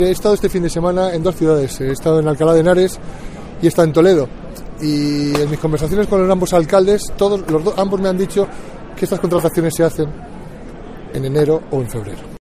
José Mazarías, alcalde de Segovia, sobre sus conversaciones con los alcaldes de Toledo y Alcalá